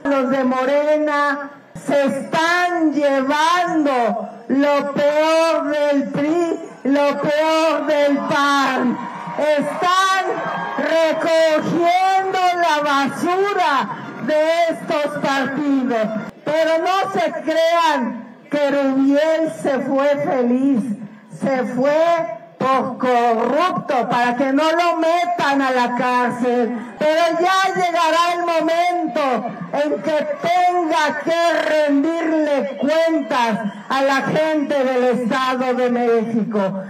Durante su cierre de campaña en Los Reyes, La Paz en el Estado de México, Xóchitl Gálvez, candidata de la alianza “Fuerza y Corazón por México” arremetió contra aquellos políticos que traicionan a sus partidos por ir detrás de sus intereses personales.